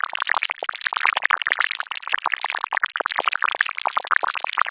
slime_001.ogg